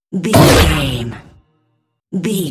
Dramatic hit creature
Sound Effects
Atonal
heavy
intense
dark
aggressive
hits